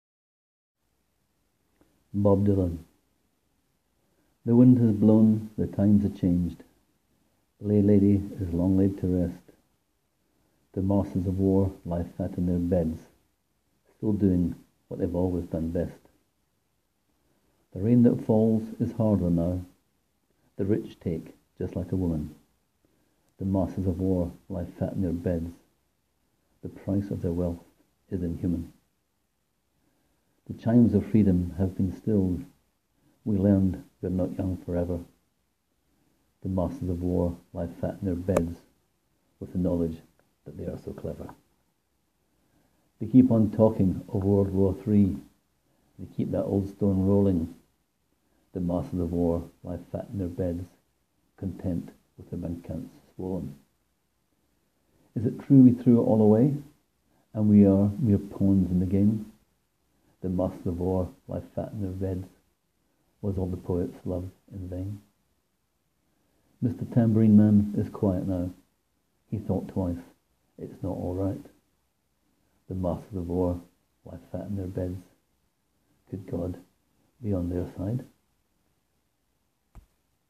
Click here to hear me read the poem: